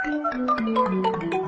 numbers_fly_out.ogg